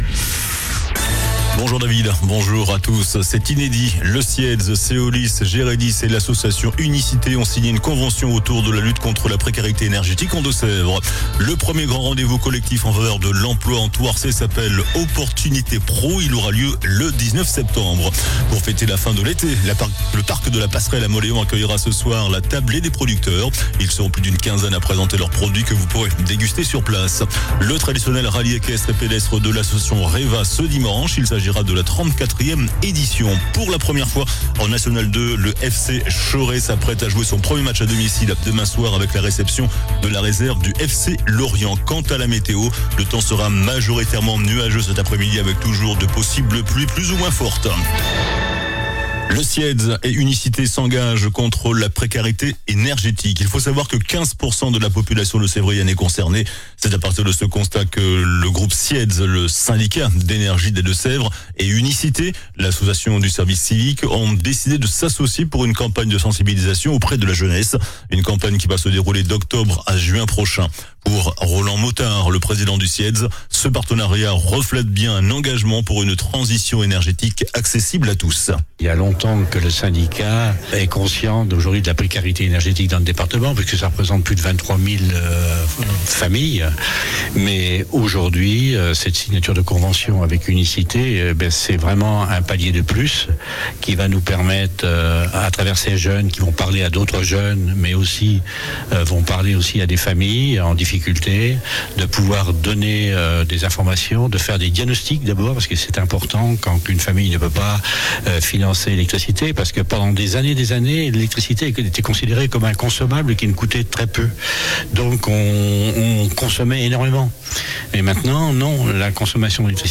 JOURNAL DU VENDREDI 29 AOÛT ( MIDI )